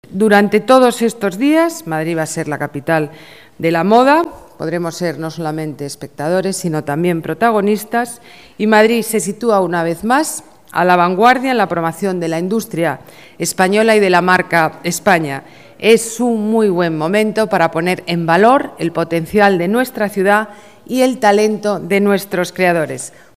La alcaldesa Ana Botella asiste a la presentación del Programa de Actividades
Nueva ventana:Declaraciones Ana Botella: Madrid es moda en Conde Duque